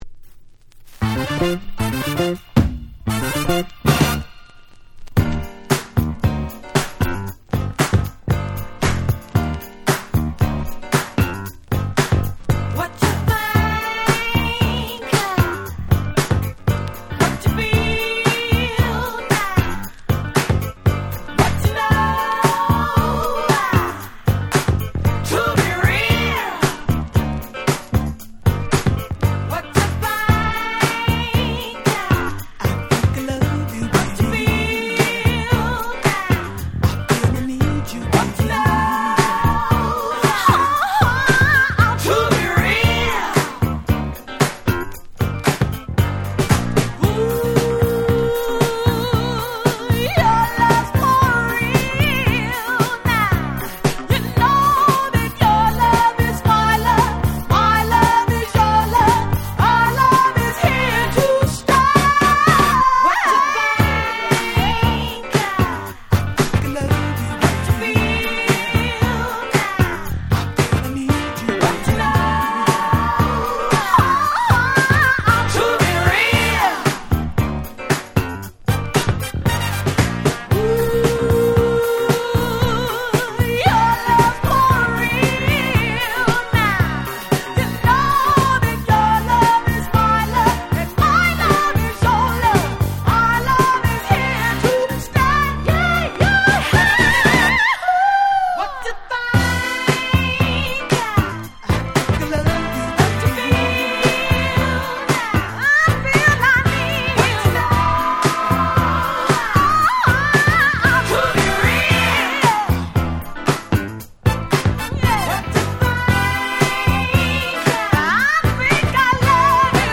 78' Super Hit Disco/Dance Classics !!